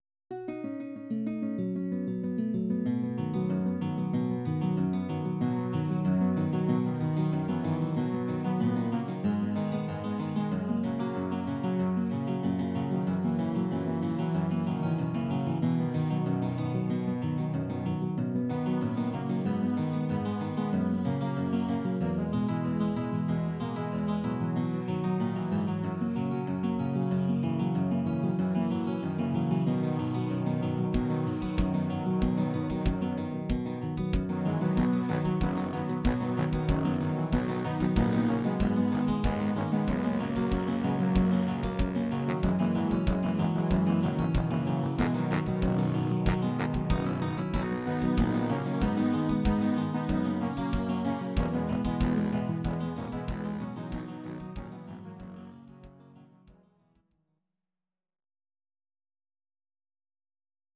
These are MP3 versions of our MIDI file catalogue.